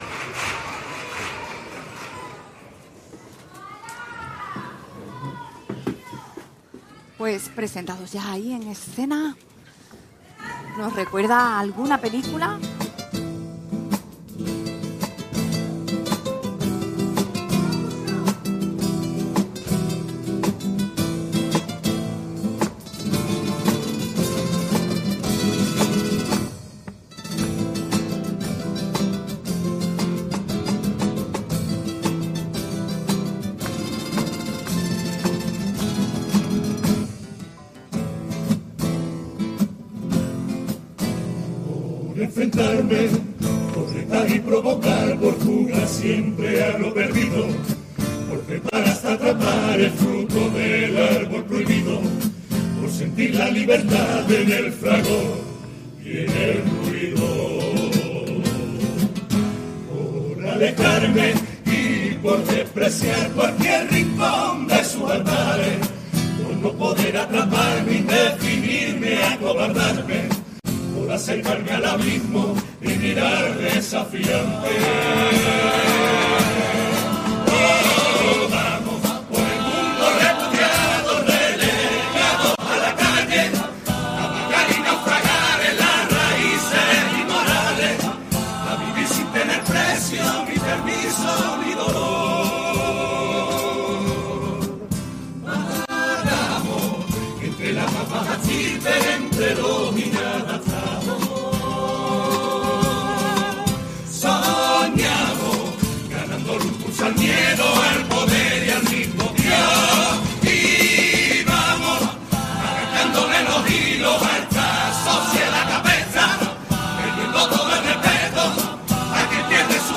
Concurso Oficial de Agrupaciones del Carnaval de Cádiz